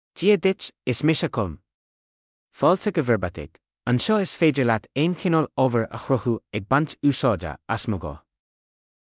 Colm — Male Irish AI voice
Colm is a male AI voice for Irish (Ireland).
Voice sample
Listen to Colm's male Irish voice.
Male